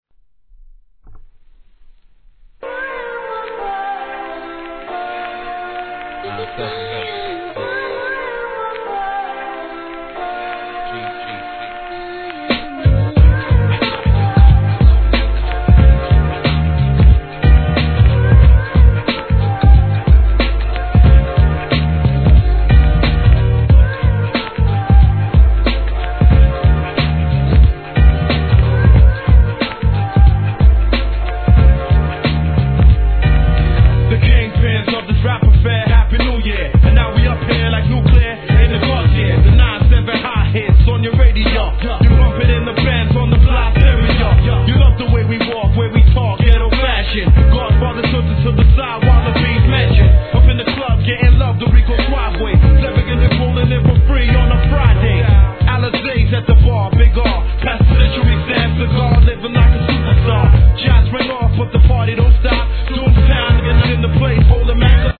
G-RAP/WEST COAST/SOUTH
王道WESTCOAST風なキャッチーなメロディ〜ラインとフックで人気のシングル!!